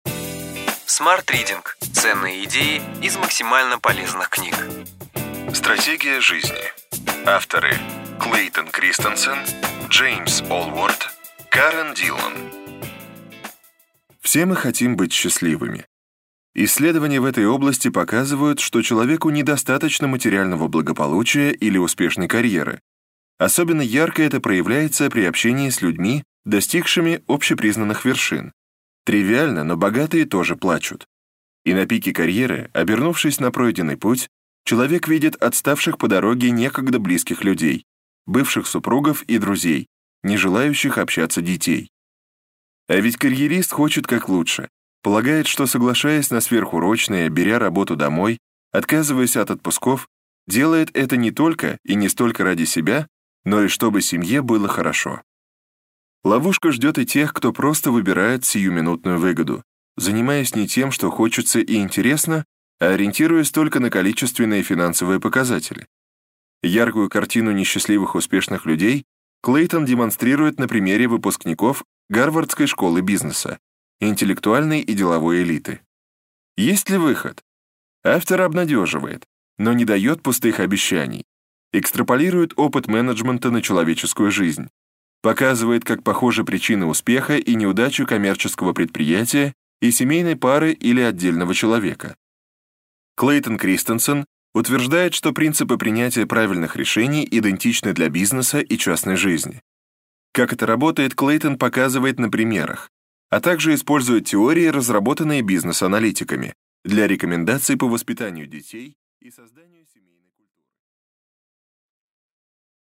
Аудиокнига Ключевые идеи книги: Стратегия жизни.